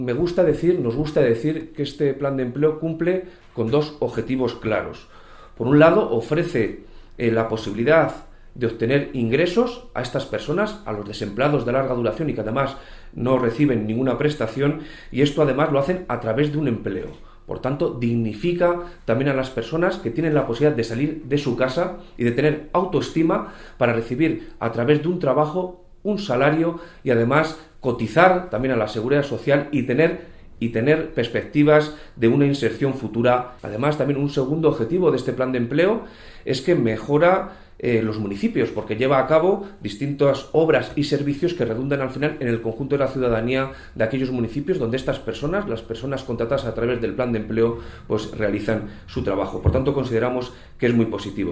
El delegado de la Junta en Guadalajra habla de los objetivos que persigue el Plan de Empleo